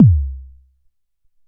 • Warm Sounding Bass Drum Single Hit E Key 194.wav
Royality free steel kick drum sample tuned to the E note. Loudest frequency: 172Hz
warm-sounding-bass-drum-single-hit-e-key-194-nG8.wav